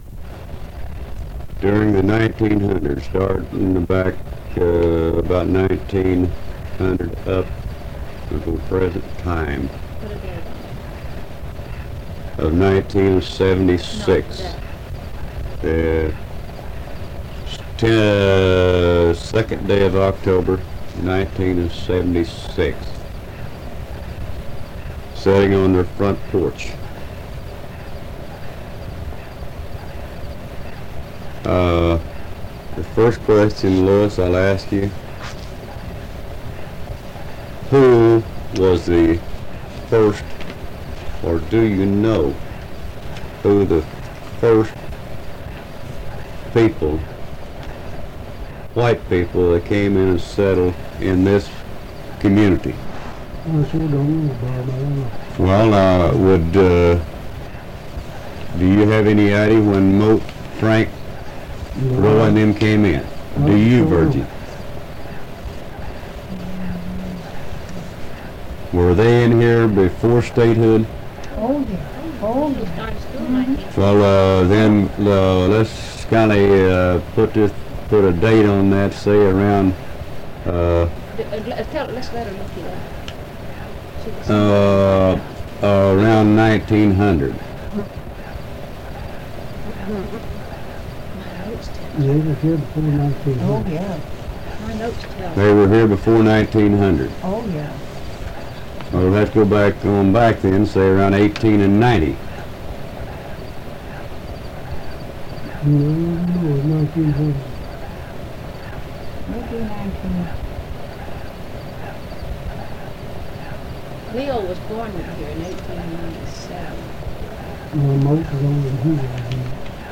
Oral History Archive | Pinehill Community